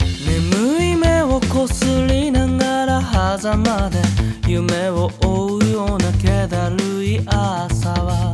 歌モノ楽曲
ボーカル
Exciterで、少しロックっぽいザラザラとした感じを追加しています。
初期状態だと歯擦音を抑えるディエッサーの効きが強かったので、若干抑えました。
ただ、この段階だと、声の厚みが少し足りないようにも感じました。
声だけが浮いて聞こえるような感じです。